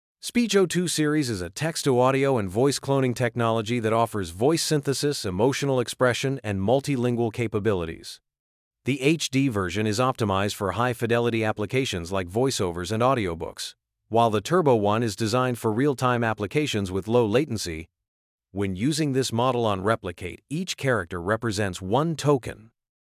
A real-time text-to-audio model with voice cloning, emotional expression controls, and multilingual support.
"channel": "mono",
"emotion": "angry",
"voice_id": "Deep_Voice_Man",